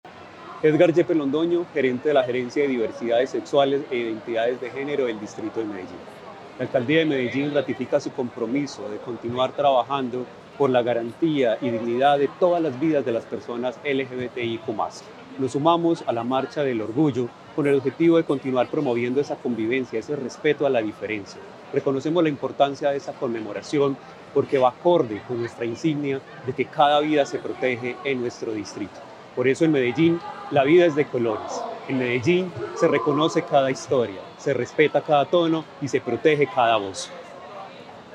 Declaraciones del gerente de Diversidades Sexuales e Identidades de Género, Edgar Yepes
Declaraciones-del-gerente-de-Diversidades-Sexuales-e-Identidades-de-Genero-Edgar-Yepes.mp3